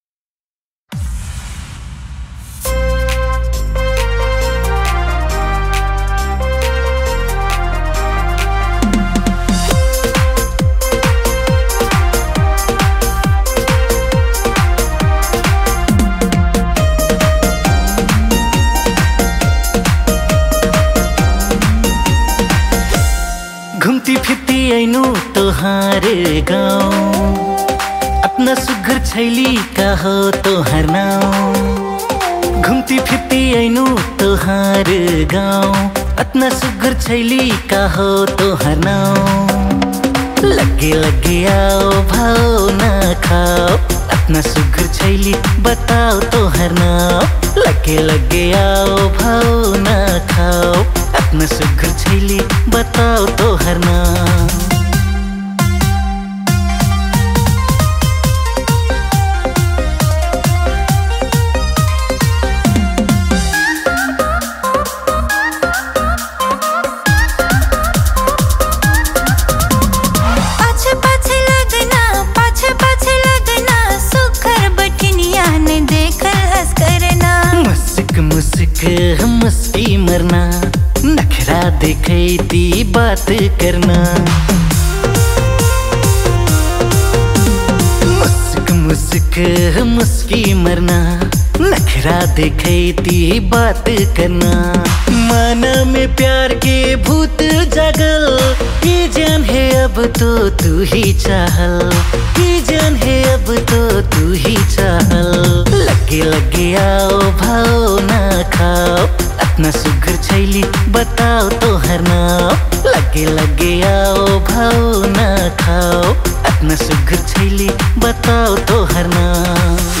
Tharu Romantic Song Tharu Item Dancing Song